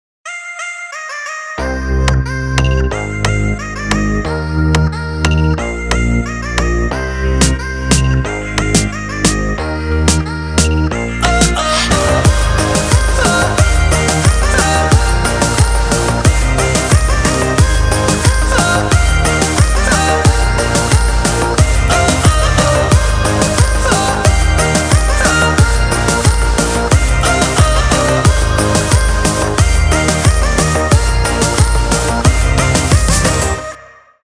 • Качество: 256, Stereo
поп
dance
Отрезок песни без слов. Только музыка.